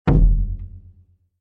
surdo-6.mp3